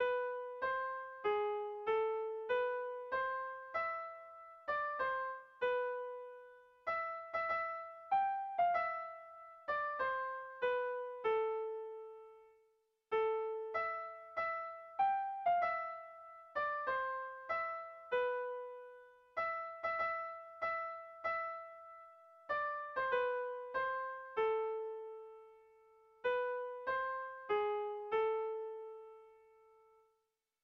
Sehaskakoa
ABDE